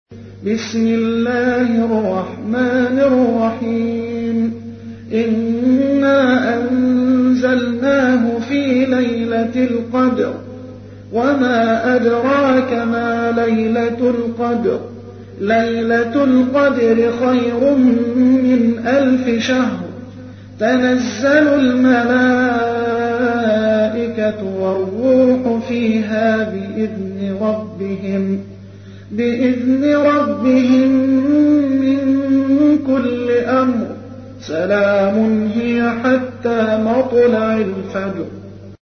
تحميل : 97. سورة القدر / القارئ محمد حسان / القرآن الكريم / موقع يا حسين